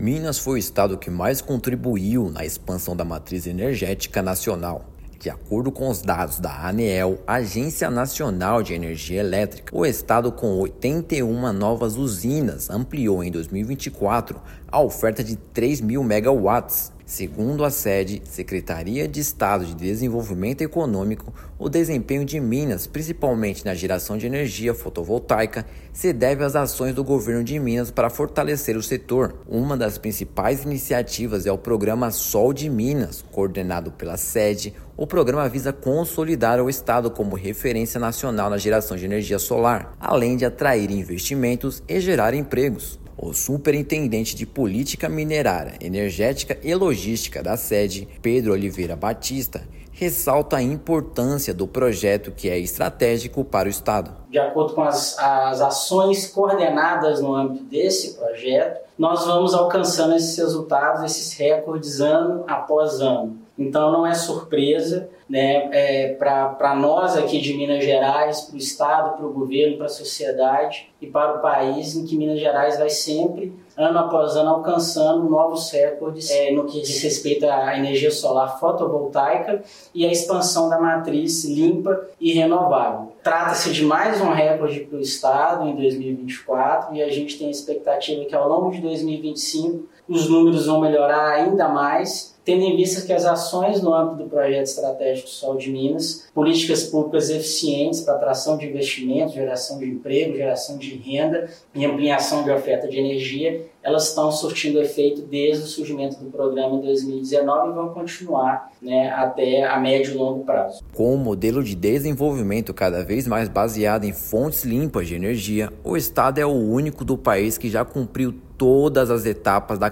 [RÁDIO] Minas Gerais lidera ampliação da matriz energética brasileira em 2024
As usinas de energia solar fotovoltaica contribuíram positivamente para a marca do estado. Ouça matéria de rádio.